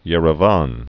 (yĕrə-vän)